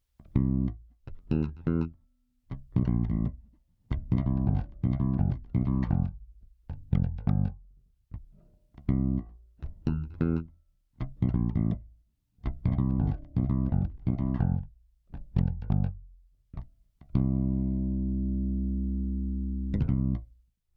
ベースラインでこの曲なーんだ？